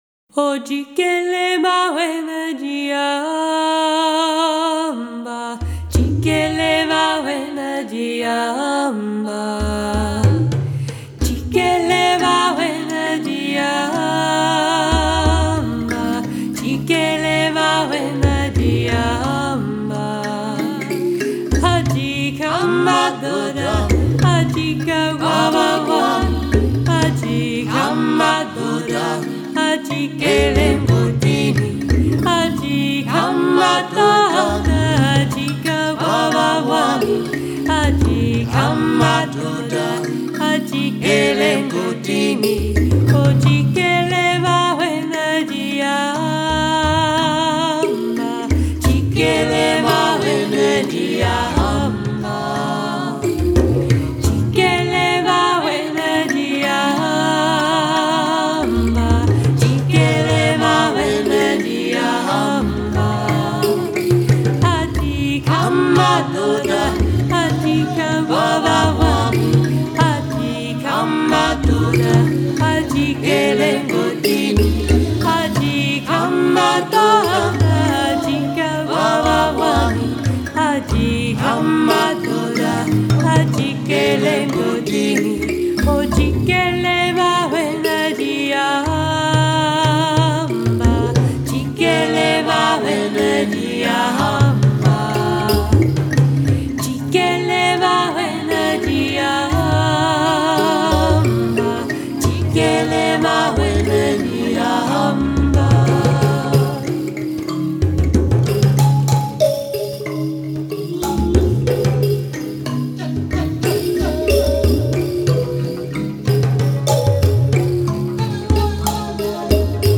kalimba
sax, bass and balafon.